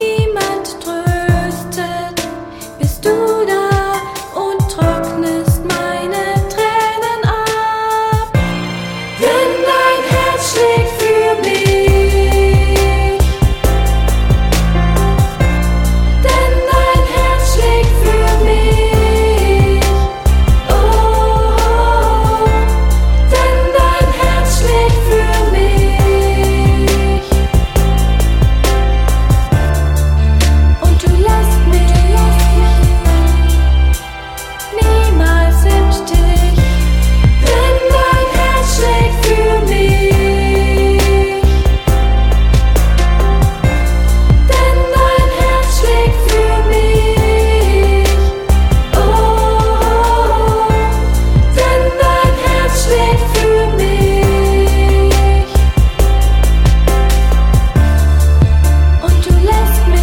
• Sachgebiet: Kinderlieder